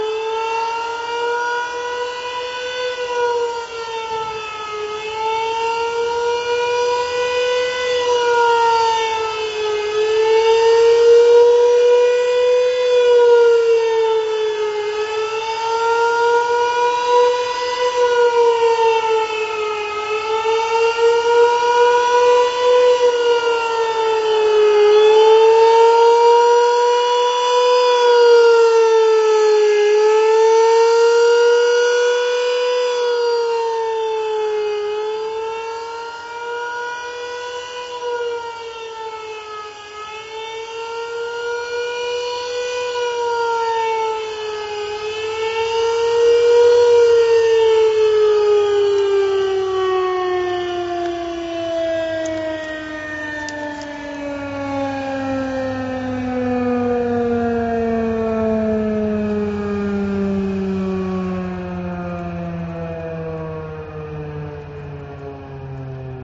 Fire / Rescue Siren Tone
Alternating high/low tone for approximately three minutes.
Fire_Rescue-Siren.mp3